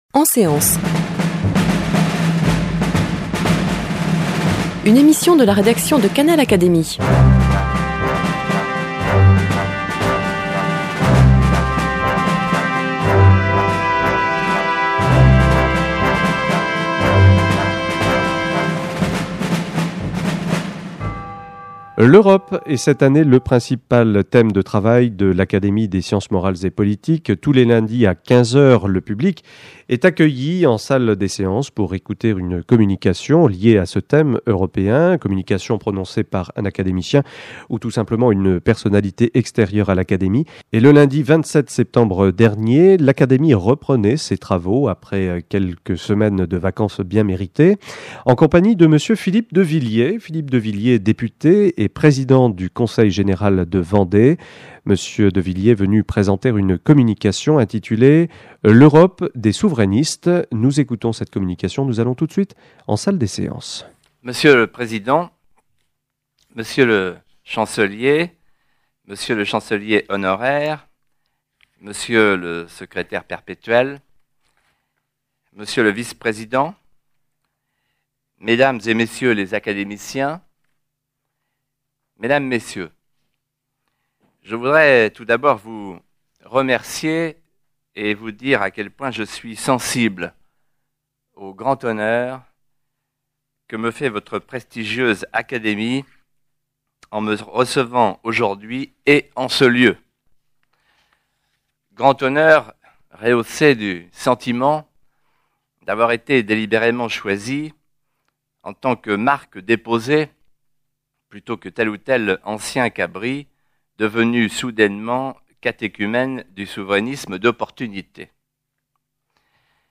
Retransmission de la communication de M. Philippe de Villiers devant les membres de l’Académie des sciences morales et politiques le lundi 27 septembre 2004.